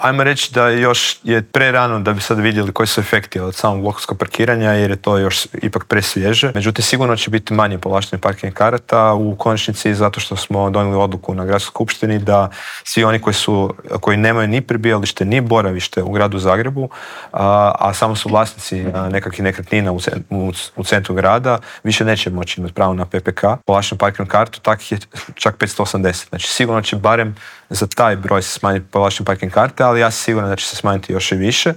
ZAGREB - Blokovsko parkiranje, nikad više automobila u metropoli - najavljena je izgradnja nove infrastrukture - gradit će se Jarunski most, proširiti tramvajska mreža na Sarajevskoj cesti - što nas sve čeka u idućim godinama u metropoli u Intervjuu tjedna Media servisa rekao nam je zagrebački gradonačelnik Tomislav Tomašević - otkrio je i kada možemo očekivati završetak novog maksimirskog stadiona.